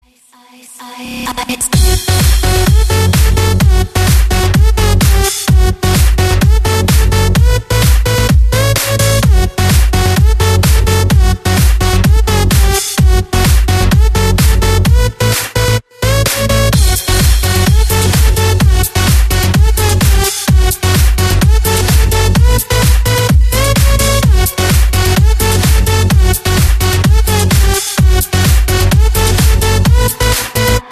• Качество: 128, Stereo
громкие
зажигательные
энергичные
быстрые
electro house
Заводной клубняк от неизвестного исполнителя.